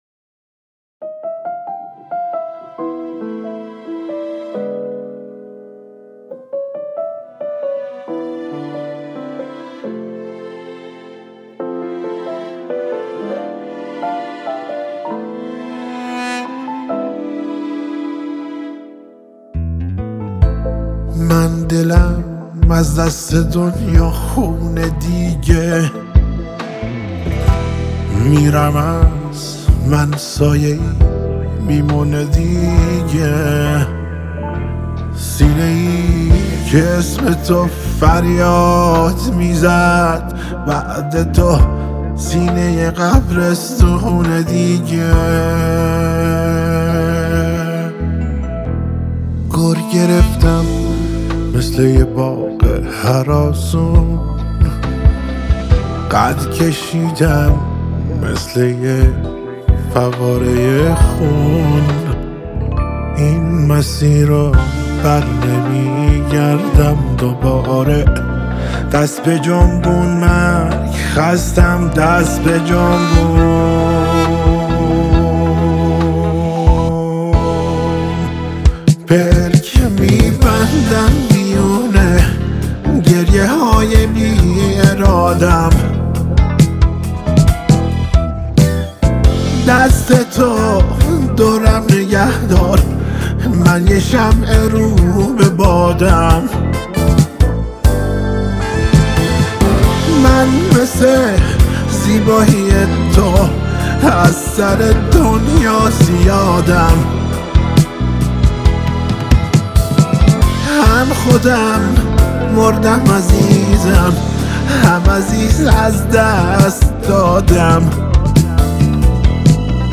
دودوک